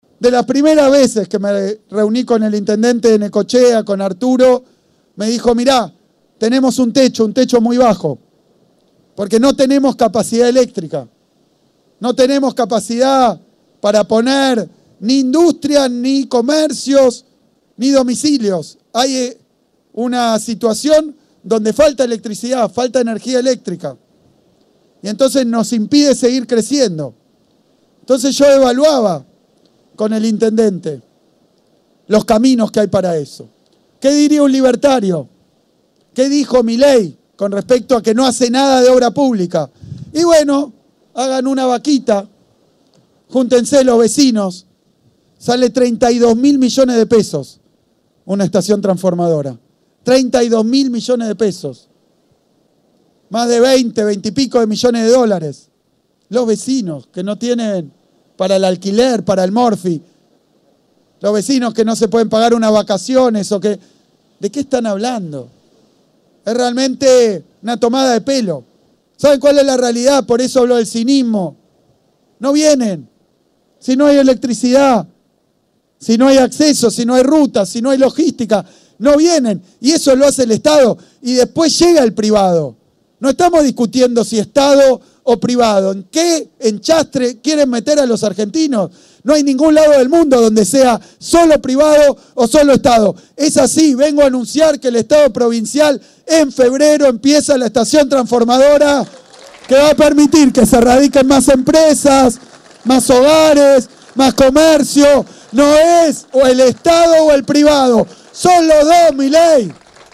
En el marco de una nueva visita al distrito y la conferencia de verano que encabezó en Quequén, el gobernador de la provincia de Buenos Aires, Axel Kicillof, anunció el comienzo de las obras de la planta de potenciación de energía eléctrica que fortalecerá el sistema energético del distrito de Necochea.
Durante su exposición, y luego de las palabras alusivas del intendente Rojas, el Gobernador confirmó que la obra comenzará en las próximas semanas y remarcó que se trata de una inversión estratégica para ampliar la capacidad de abastecimiento eléctrico, mejorar la calidad del servicio y acompañar el crecimiento productivo, industrial y turístico de la región.